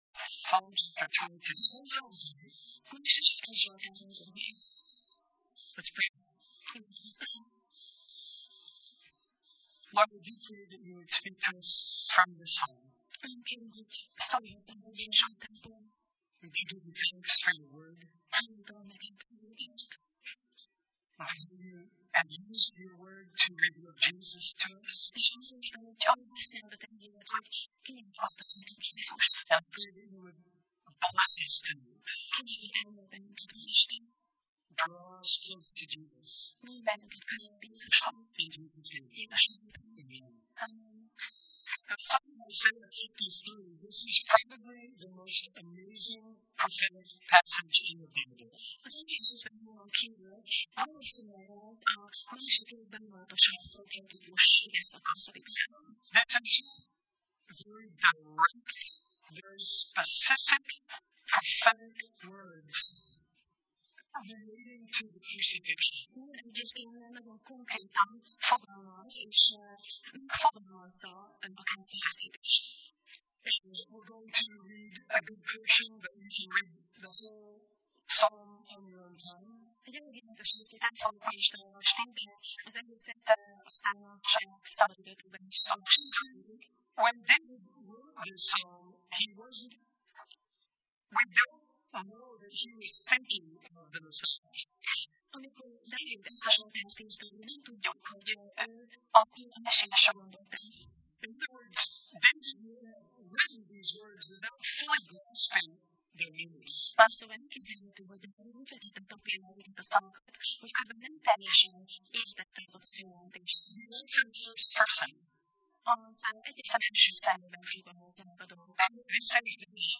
Sorozat: Zsoltárok Passage: Zsoltárok (Psalm) 22 Alkalom: Szerda Este